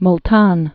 (ml-tän)